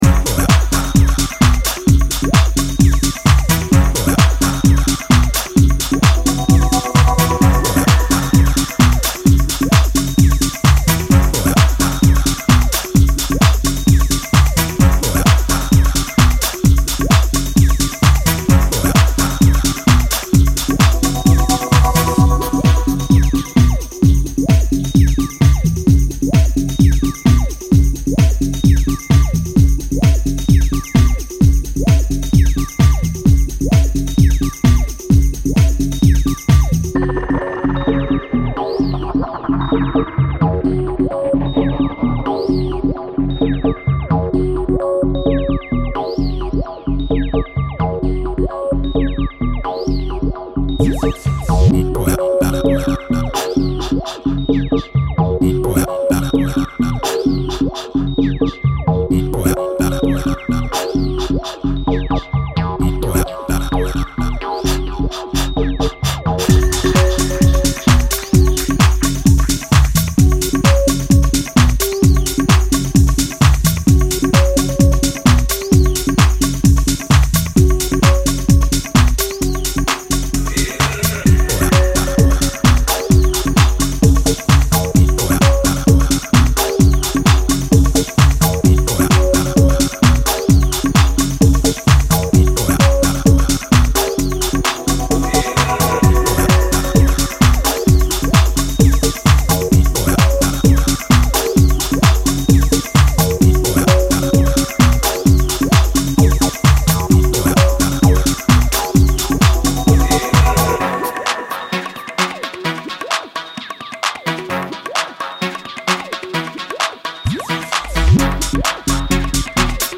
house, bass and rhythms